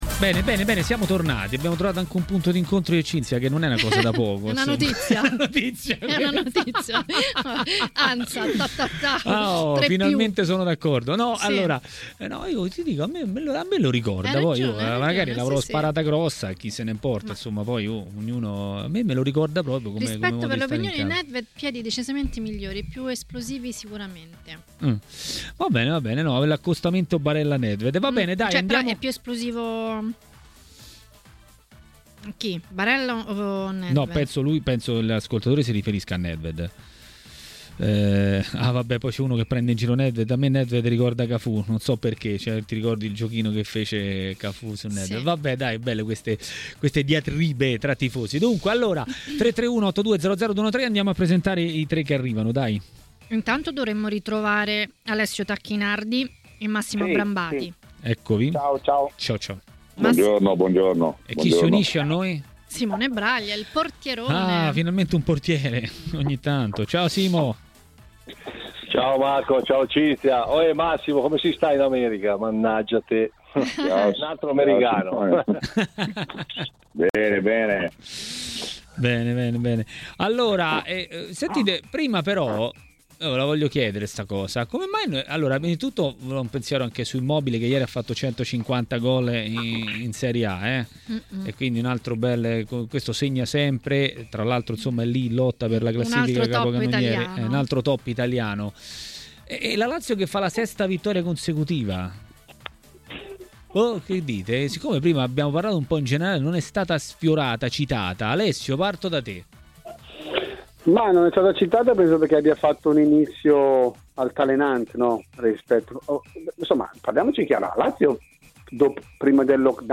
L'ex portiere Simone Braglia a Maracanà, nel pomeriggio di TMW Radio, ha parlato dei temi del momento.